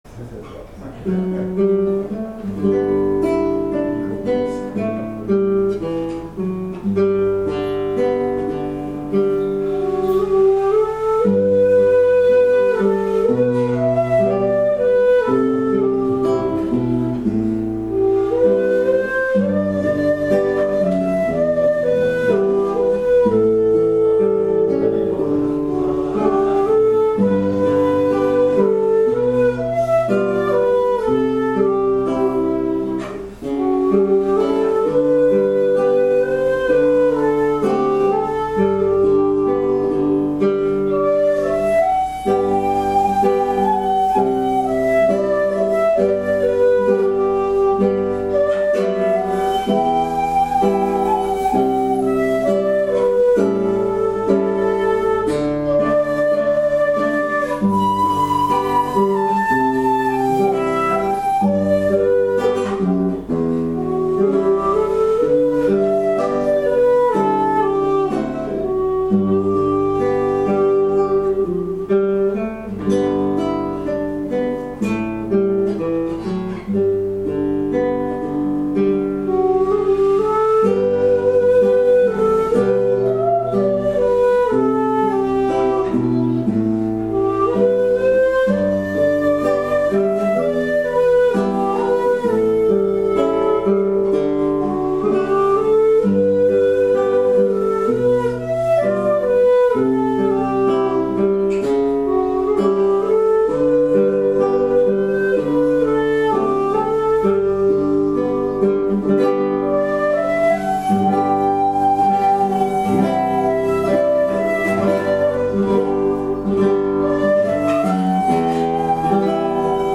～尺八とｷﾞﾀｰで奏でる～